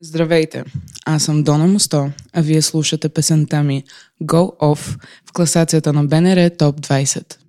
в интервю за слушателите на БНР Топ 20